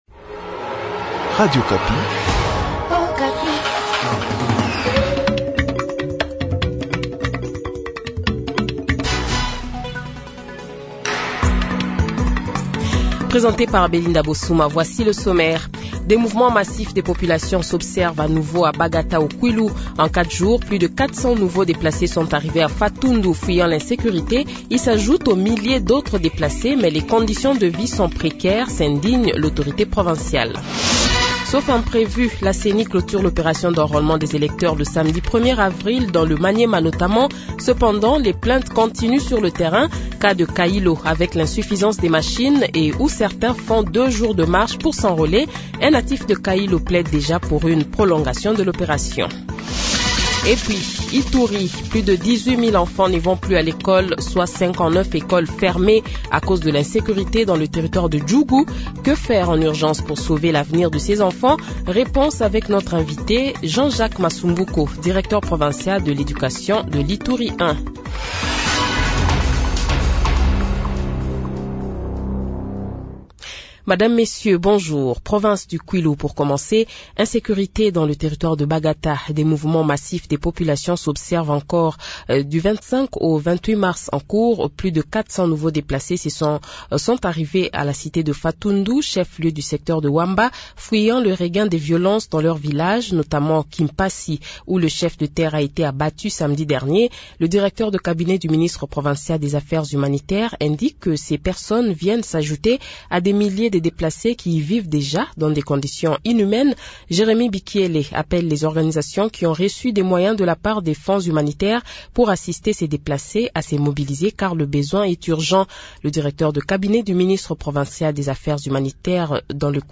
INVITE : Jean Jacques Masumbuko, directeur provincial de l’éducation de l’Ituri